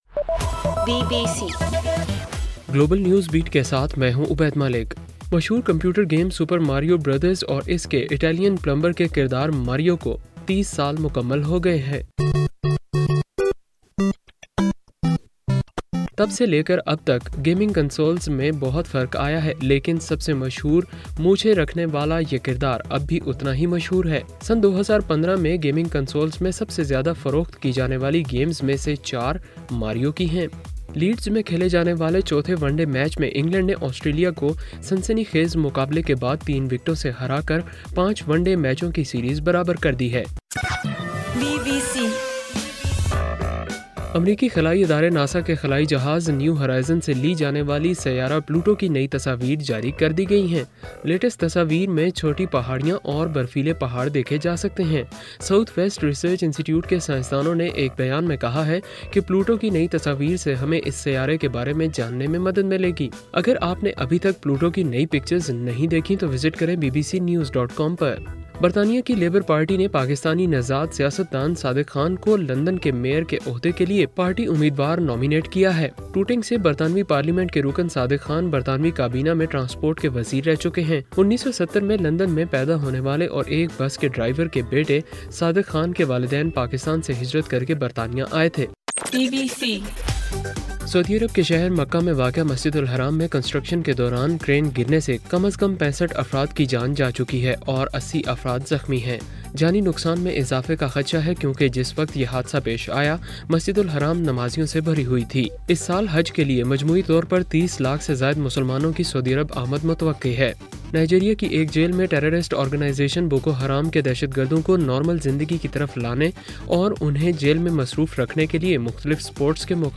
ستمبر 12: صبح 1 بجے کا گلوبل نیوز بیٹ بُلیٹن